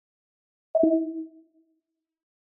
Звуки MacBook Pro и iMac скачать mp3 - Zvukitop